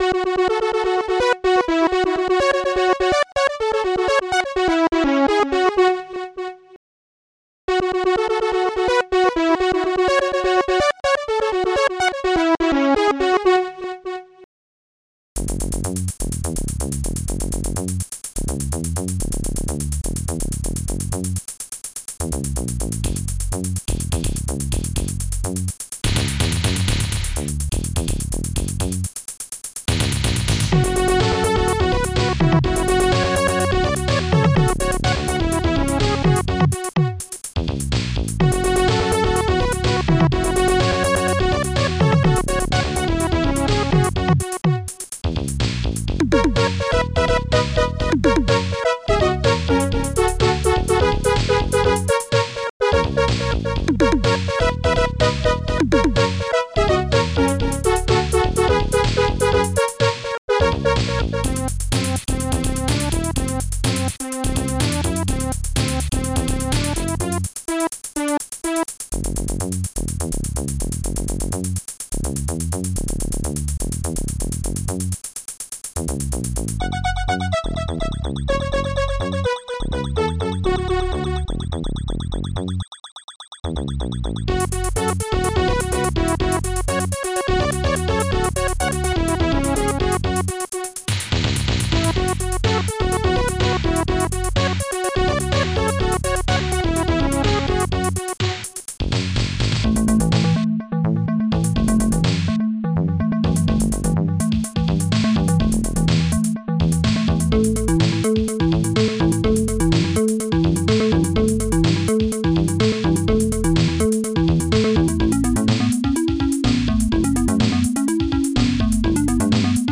Protracker and family
st-01:hallbrass
st-01:synbrass
st-01:popsnare2
st-01:bassdrum2
st-01:hihat2